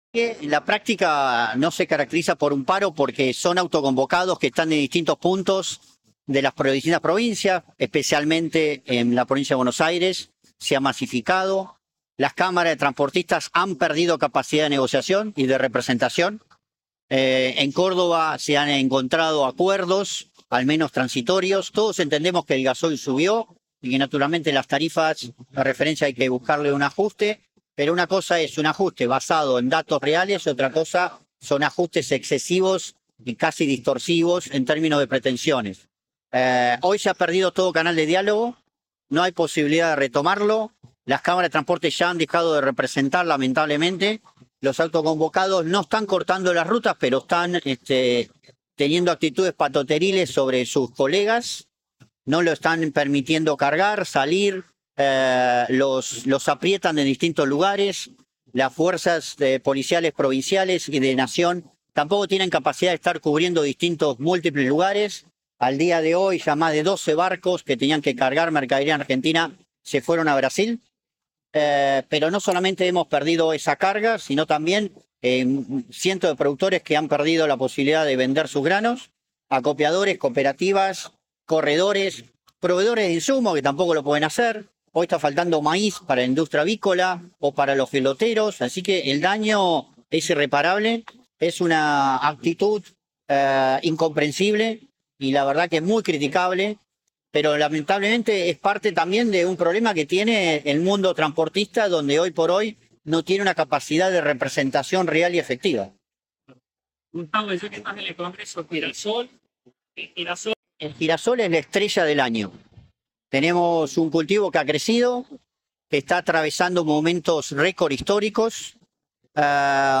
Desde Mar del Plata
en el marco del Congreso ASAGIR 2026